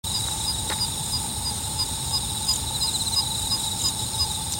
セ　ッ　カ　属   セ ッ カ １　1-04-15
鳴 き 声：飛びながらヒッヒッヒッヒッと続けて鳴き、下降する時にはチャッチャッ、チャッチャッと鳴く。
ヒッと鳴くこともある。
鳴き声１